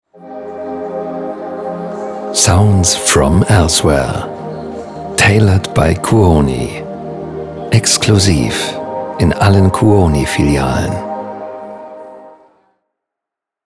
dunkel, sonor, souverän, markant, sehr variabel
Mittel plus (35-65)
Off, Station Voice